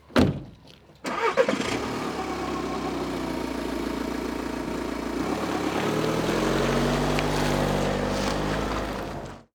A car
car-engine.wav